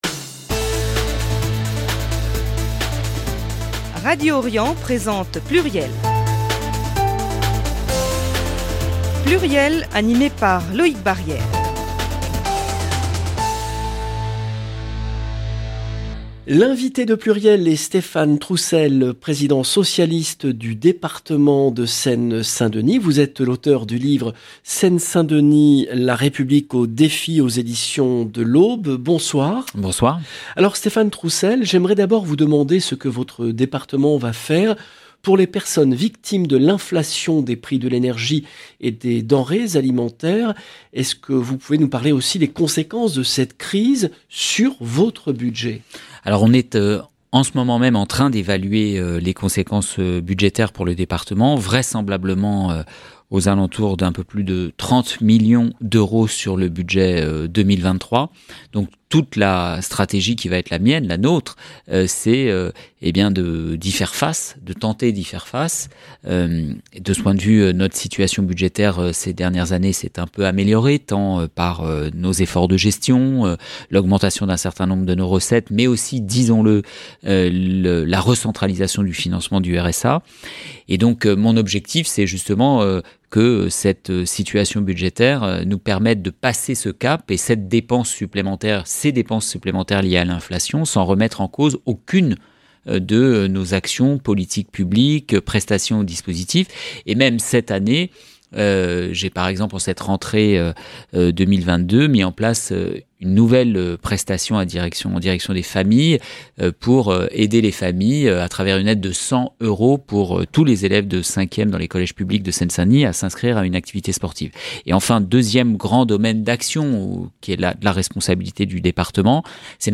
Stéphane Troussel, président PS du Département de Seine-Saint-Denis